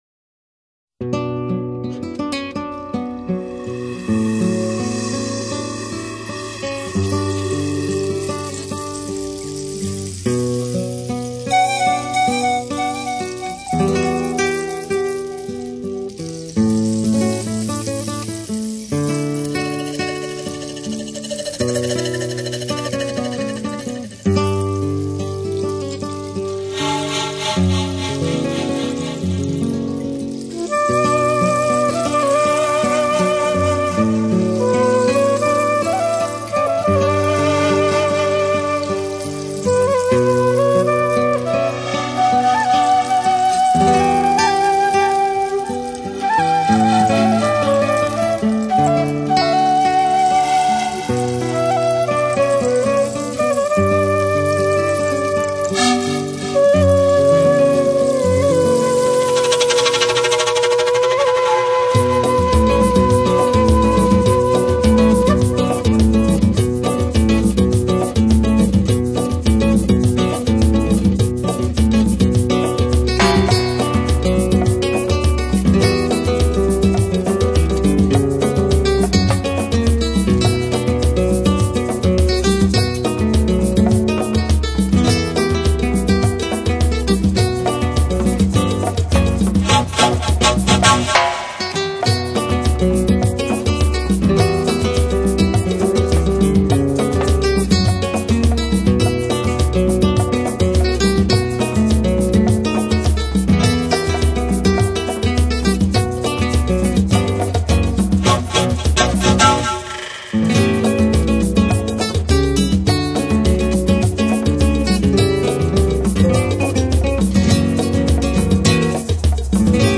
新世纪音乐的代表作！南美风格，音色透明清澈甜美自然，无人工雕琢痕 迹。